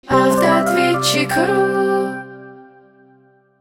Примеры звуковых логотипов
Вокальный логотип